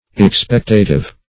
Expectative \Ex*pect"a*tive\, a. [Cf. F. expectatif.]